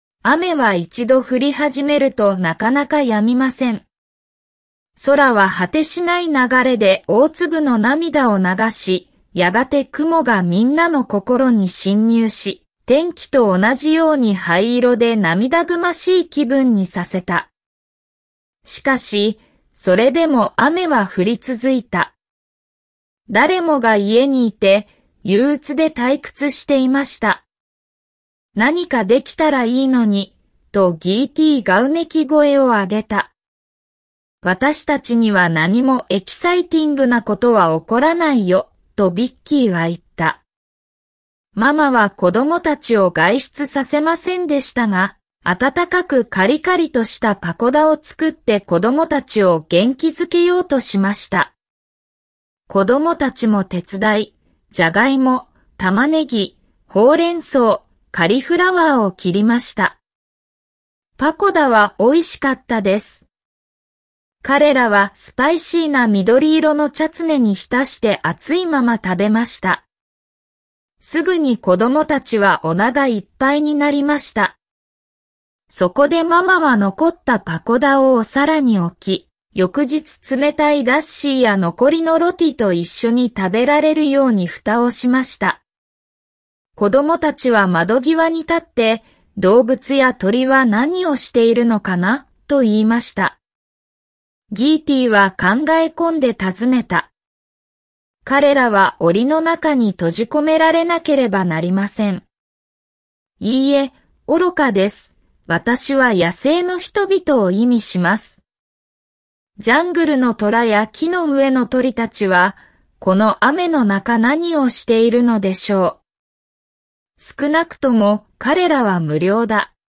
cc_picture_book2 Historias de libros ilustrados 1 ;huìběn gùshì 1